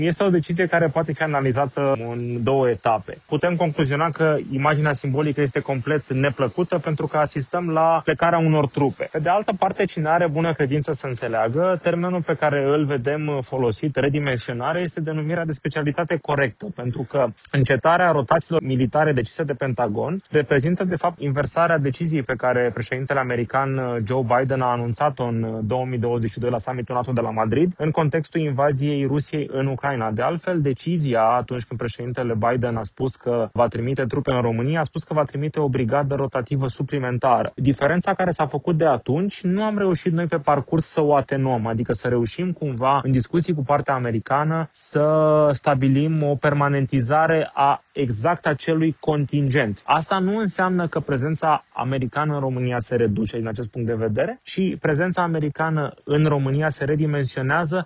într-un comentariu oferit pentru postul nostru de radio